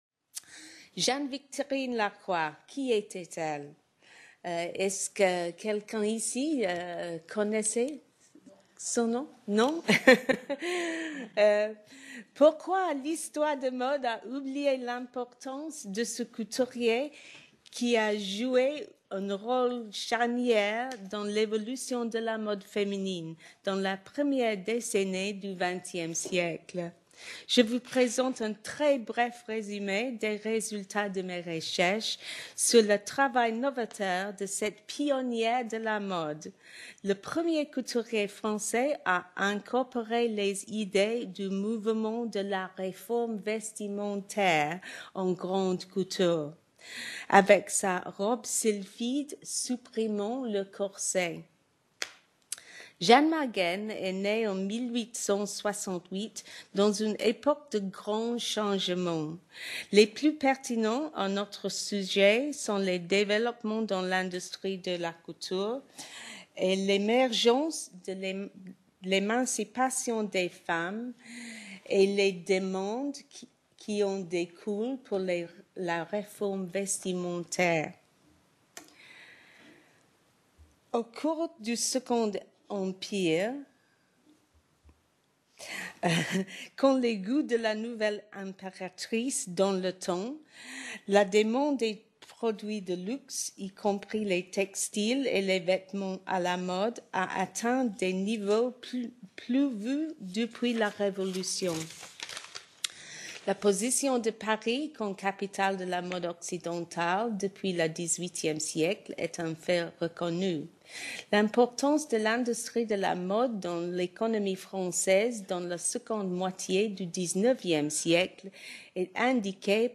Séminaire histoire de mode. Les Femmes Qui Font la Mode : Directrices, Couturières, Petites Mains.